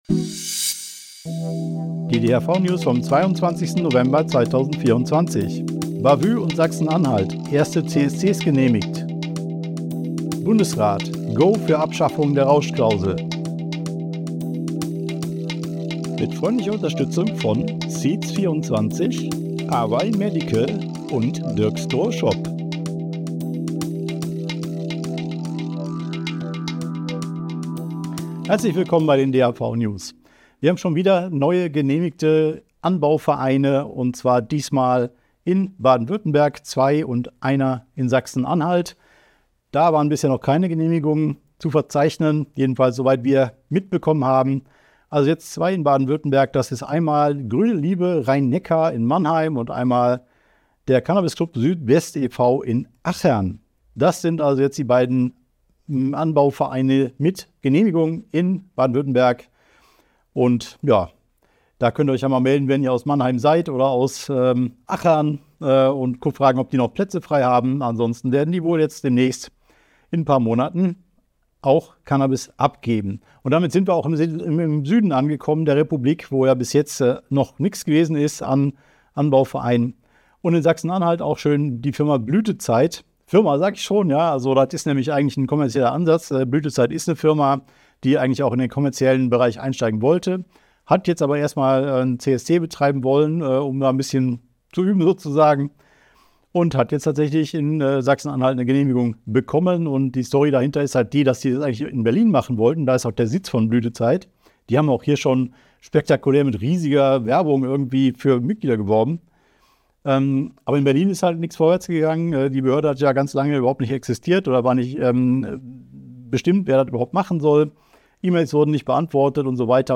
DHV-News # 445 Die Hanfverband-Videonews vom 22.11.2024 Die Tonspur der Sendung steht als Audio-Podcast am Ende dieser Nachricht zum downloaden oder direkt hören zur Verfügung.